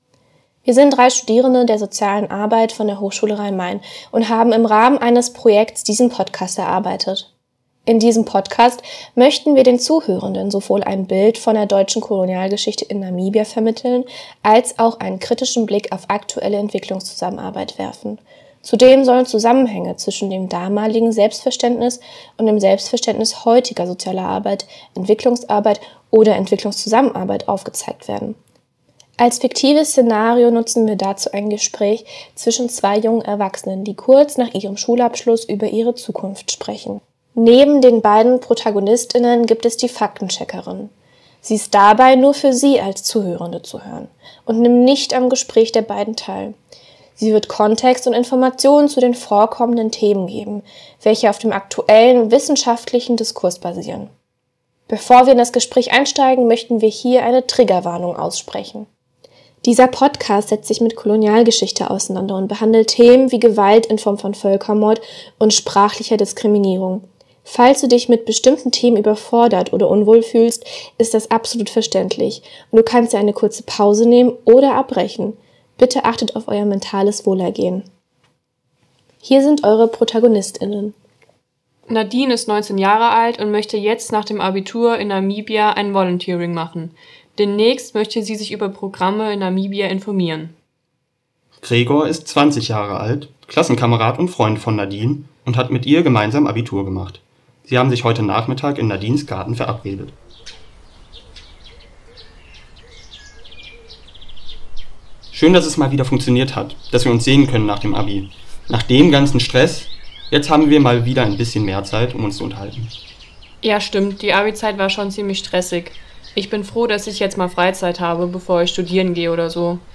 Hörspiel: Voluntourismus und Freiwilligendienste in Postkolonialen Verhältnissen
Ein Hörspiel, das sich kritisch mit Voluntourismus und Entwicklungszusammenarbeit in postkolonialen Verhältnissen beschäftigt.
Hoerspiel_Kritik_von_Voluntourismus_und_Entwicklungszusammenarbeit_in_postkolonialen_Verhaeltnissen.mp3